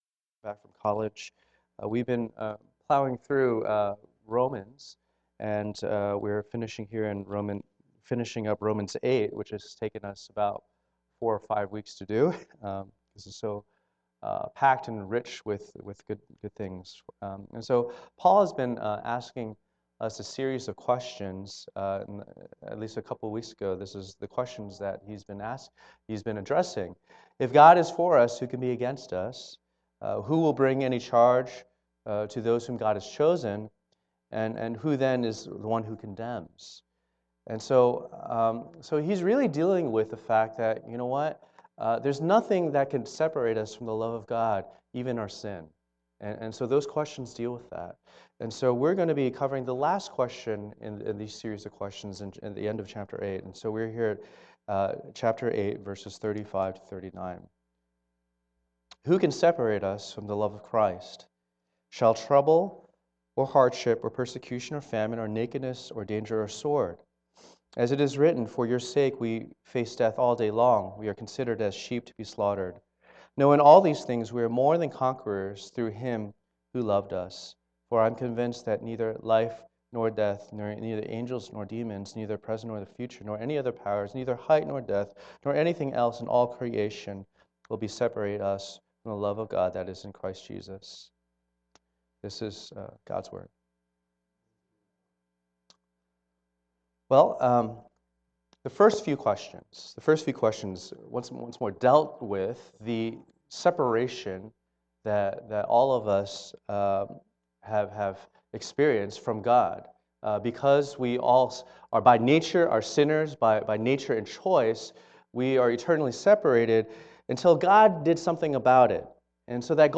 Service Type: Lord's Day